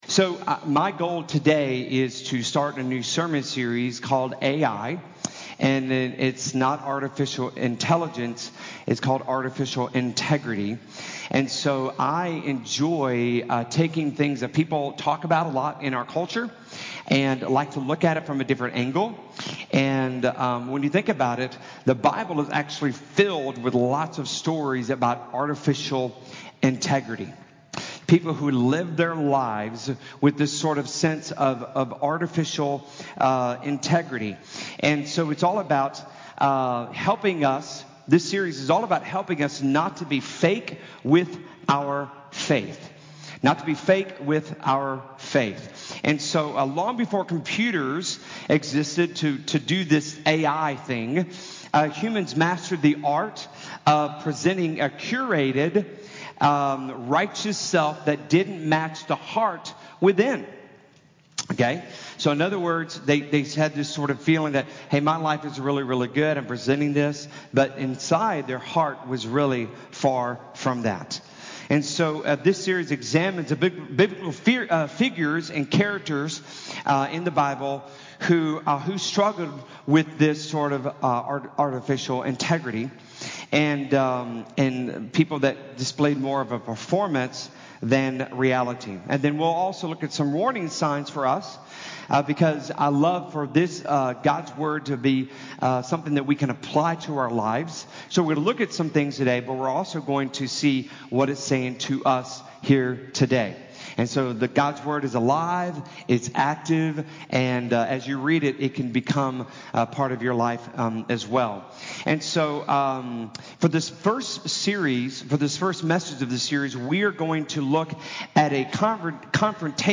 The-Pretenders-Sermon-Audio-CD.mp3